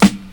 • '00s Short Hip-Hop Snare Drum G Key 221.wav
Royality free snare drum sample tuned to the G note. Loudest frequency: 1096Hz